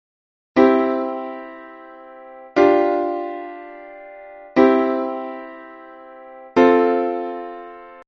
A variant of this accompanied melody emerged during the Classicism, it was called the Alberti bass (for a composer who made it popular): instead of playing the notes in the chord at the same time, they are alternated, making different drawings.
acordes.mp3